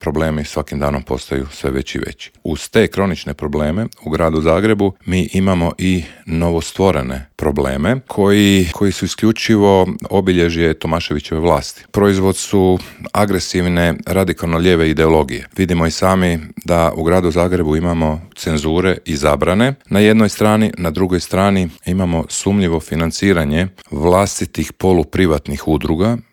ZAGREB - U Intervjuu Media servisa gostovao je predsjednik zagrebačkog HDZ-a Ivan Matijević s kojim smo prošli aktualne teme na nacionalnoj, kao i na zagrebačkoj razini.